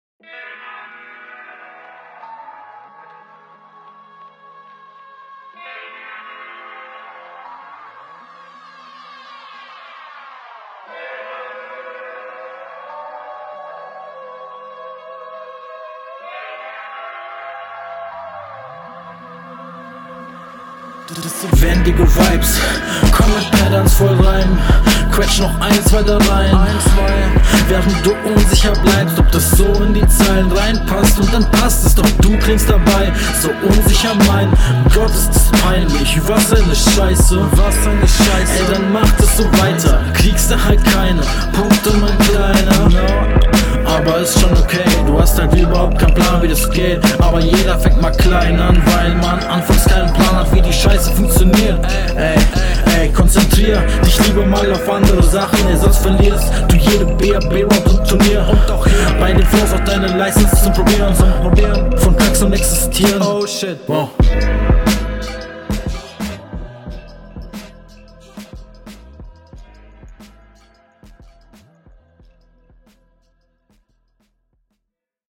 beat und deine voice machen hier richtig nice symibose. mag ich sehr. find das persönlich …